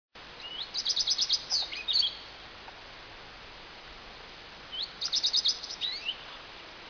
their song which is delivered from the top of the tree.
Redstart2.ogg